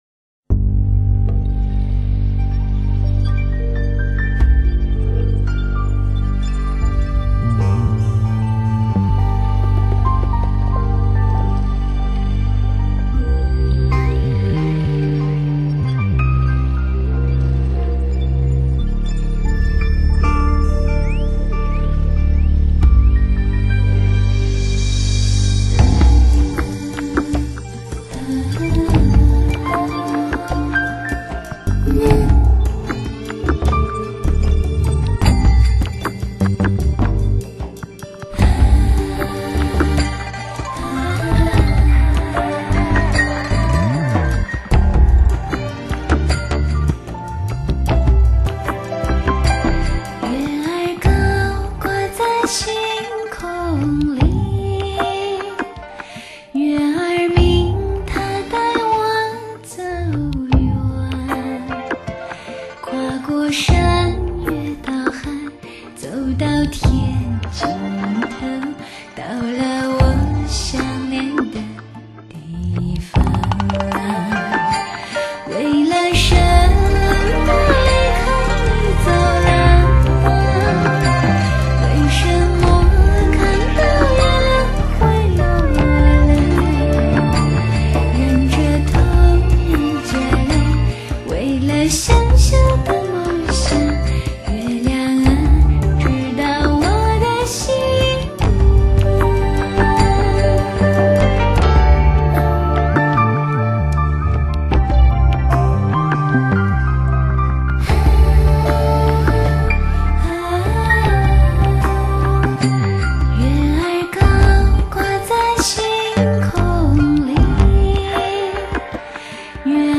音乐类型：新民乐